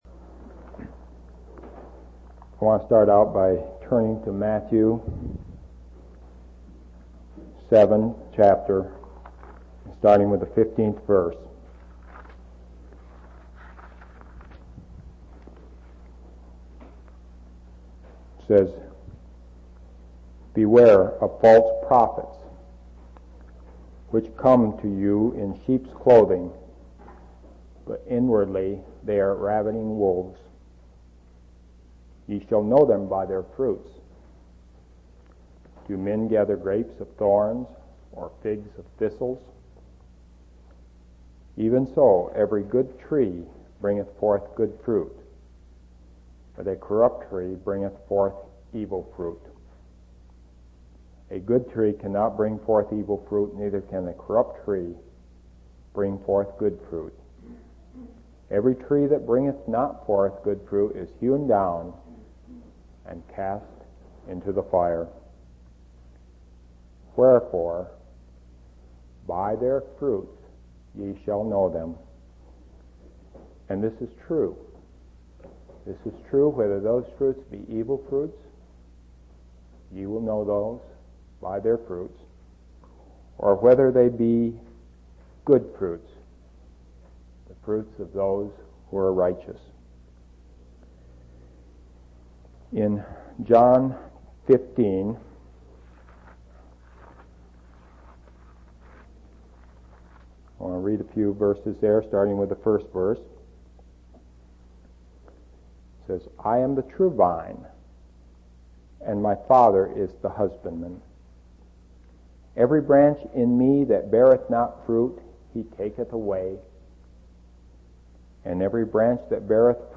11/16/1986 Location: East Independence Local Event